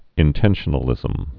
(ĭn-tĕnshə-nə-lĭzəm)